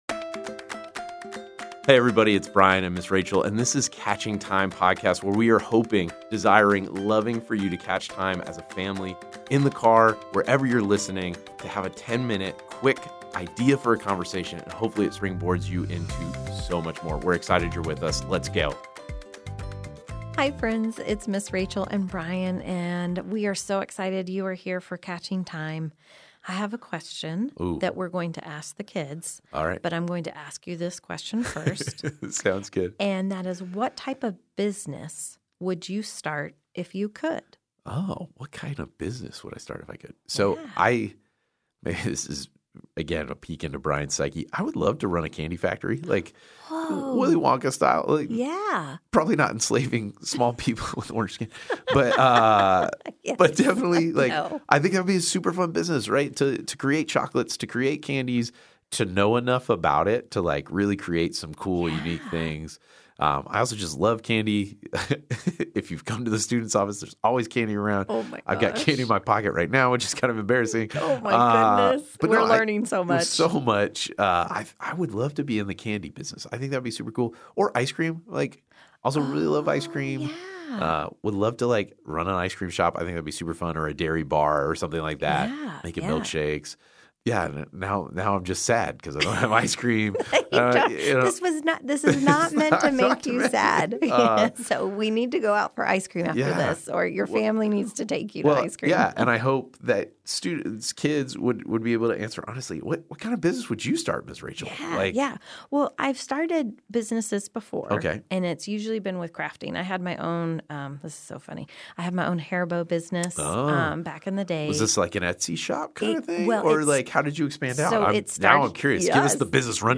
A message from the series "2024 - 2025."